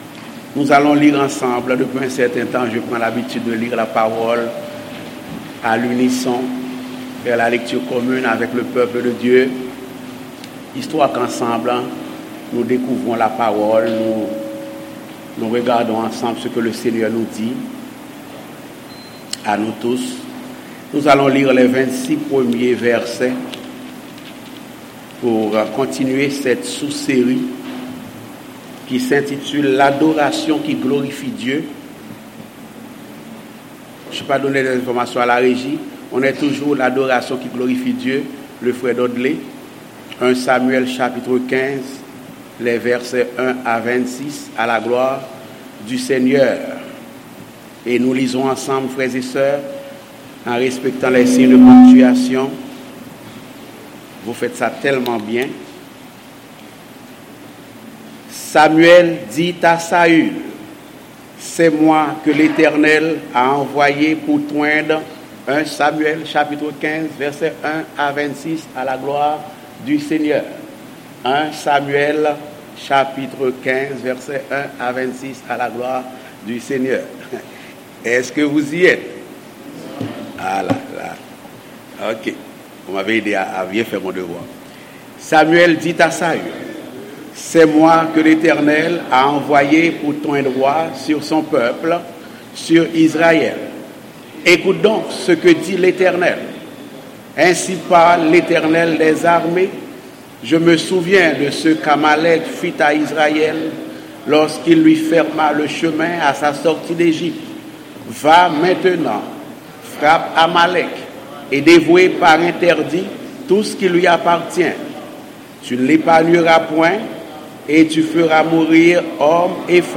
Passage: 1 samuel 15: 1-26 Type De Service: Dimanche matin